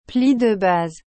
This fold is named as Pli De Base (base fold) on Hermes scarf knotting card. Feel free to play the audio recording if you are keen to know how to pronounce it.